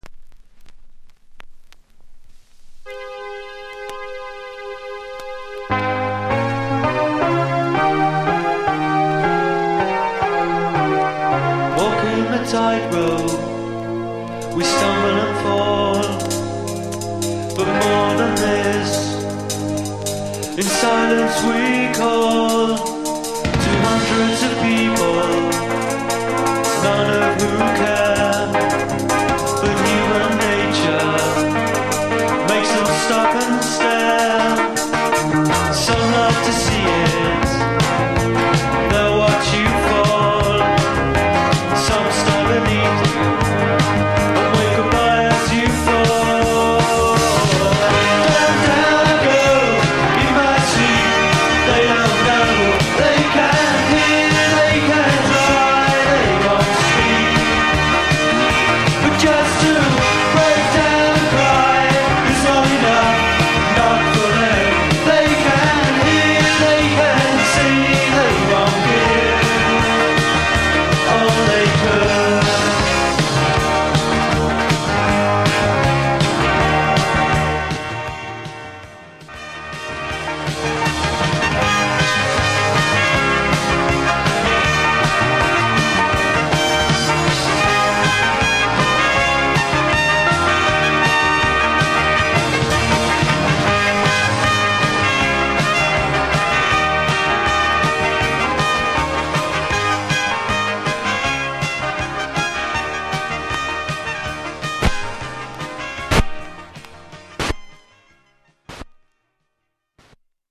(試聴後半からフェードアウ トの影響部分が確認できます)